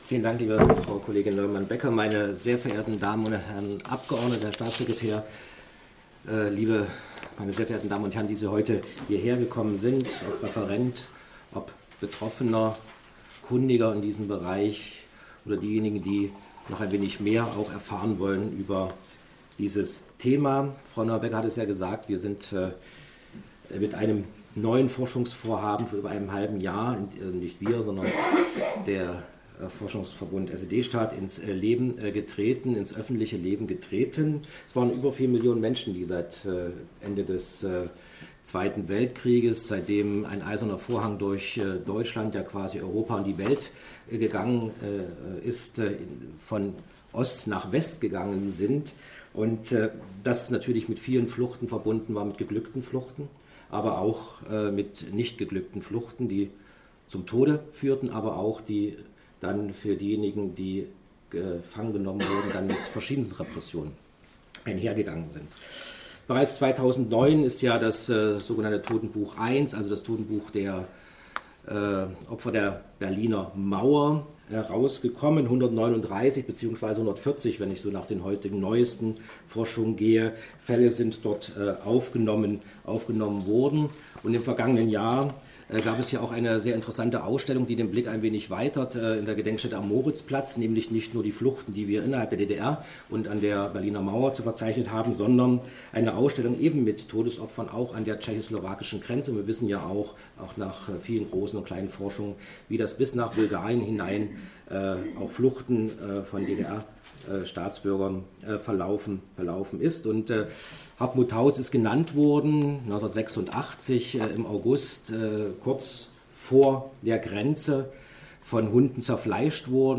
Dokumentation der Fachveranstaltung im Magdeburger Landtag 28.2.2018: „Die Todesopfer des DDR-Grenzregimes an der innerdeutschen Grenze 1949–1989 in Sachsen-Anhalt“
Begrüßung